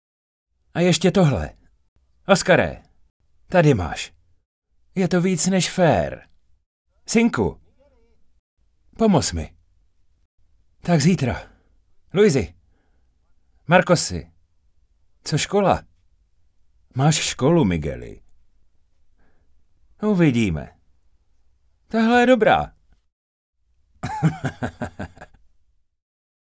Dabing: